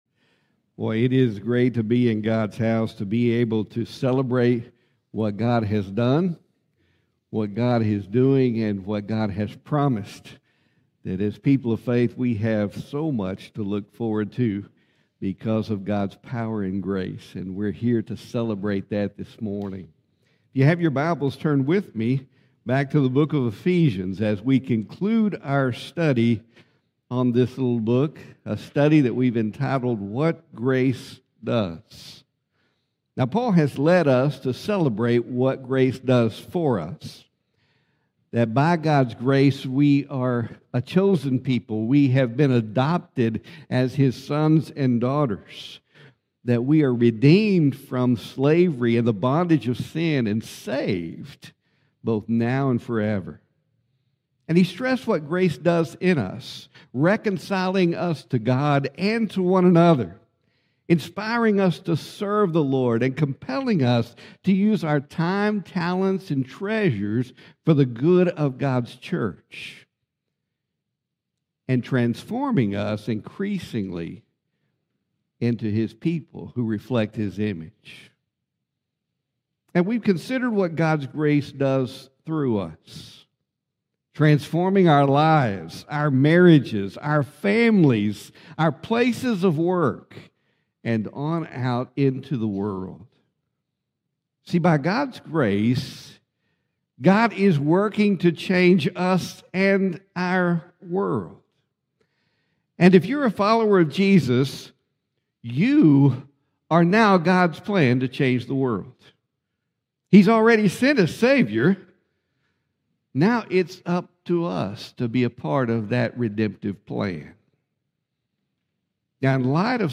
Sermons | First Baptist Church Brownwood